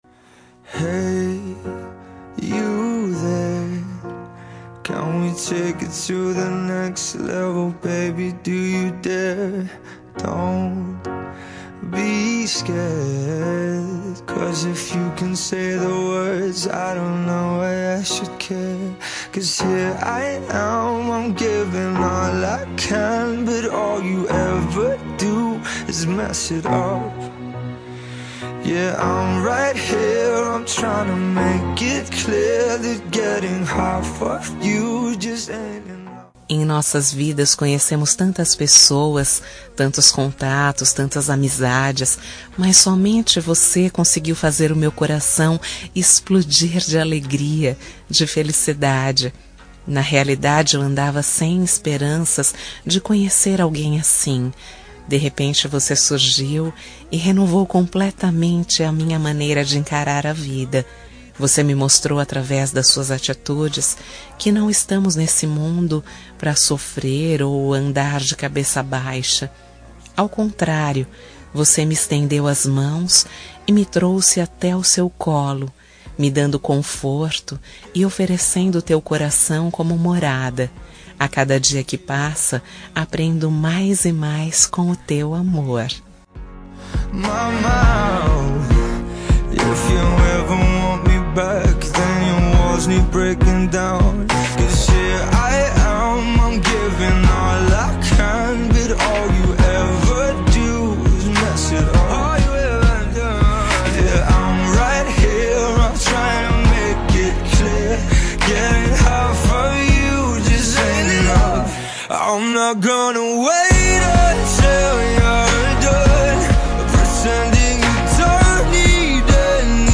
Telemensagem Romântica GLS – Voz Masculina – Cód: 5485 – Linda